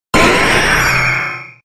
Cri de Méga-Roucarnage dans Pokémon Rubis Oméga et Saphir Alpha.